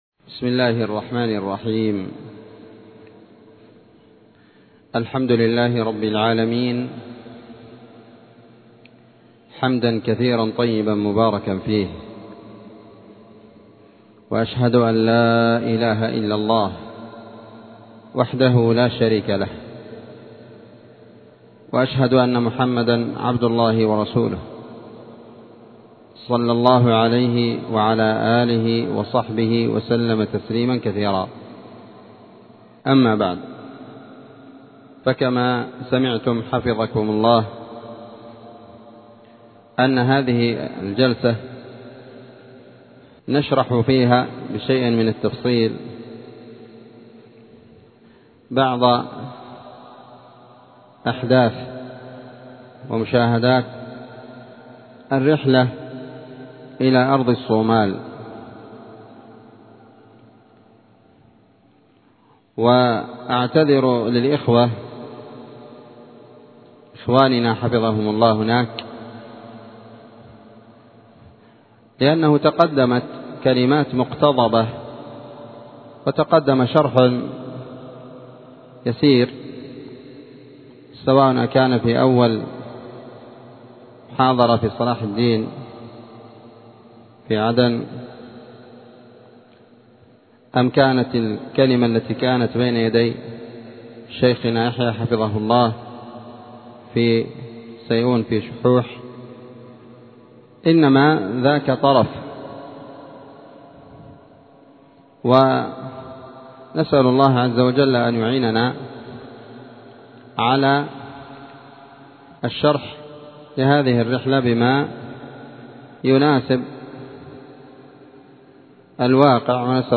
مسجد المجاهد- النسيرية- تعز